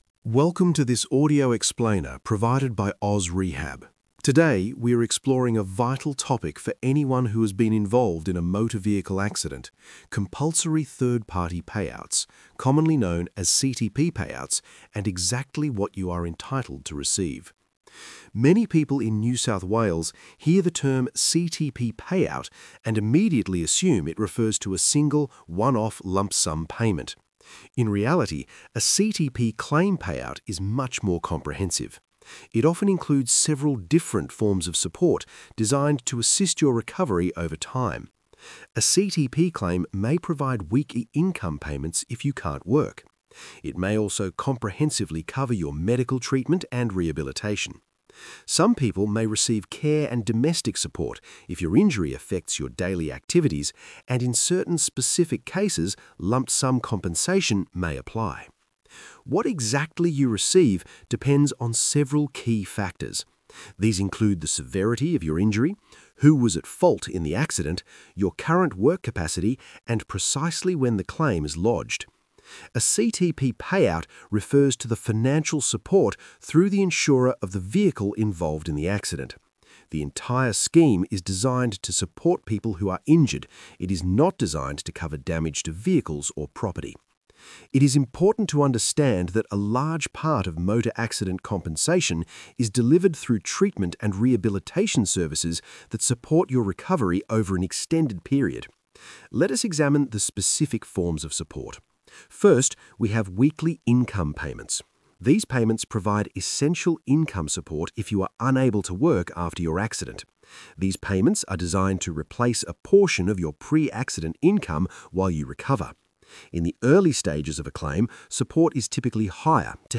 Single-host narration